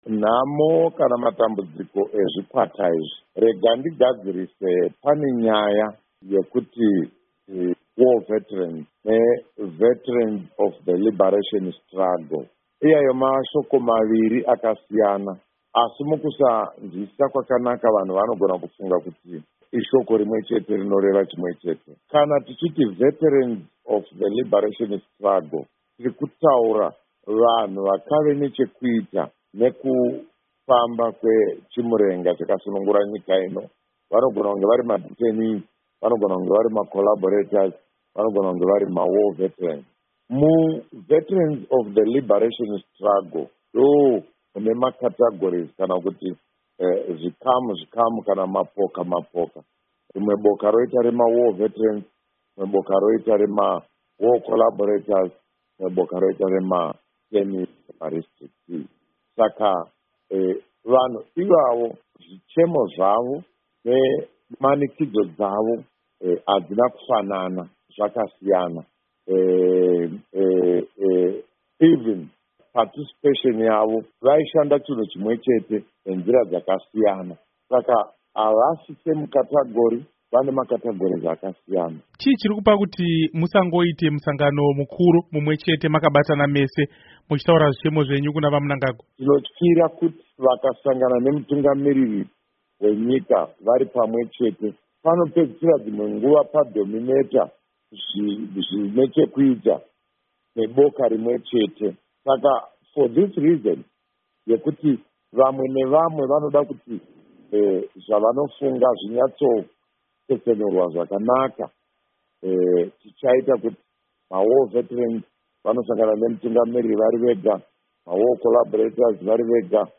Hurukuro naVaVictor Matemadanda